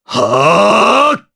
Dimael-Vox_Casting4_jp.wav